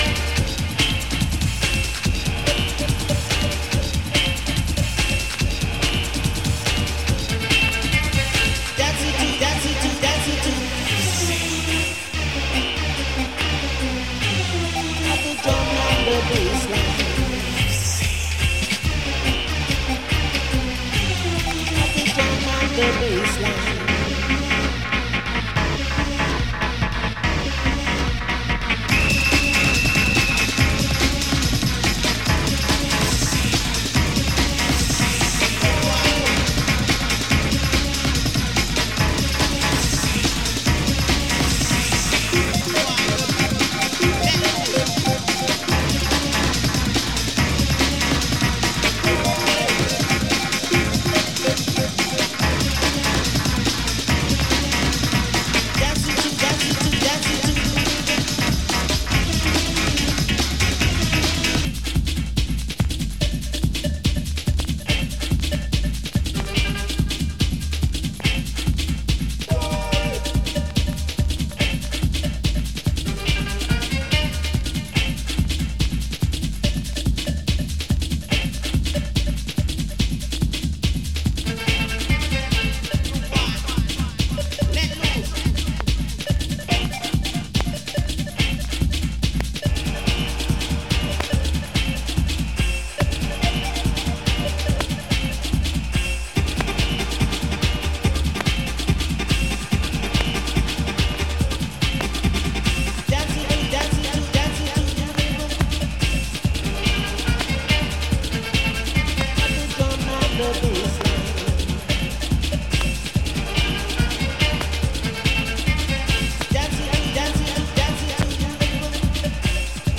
Surface scratches and marks some light noise.